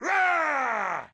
spawners_mobs_barbarian_yell2.ogg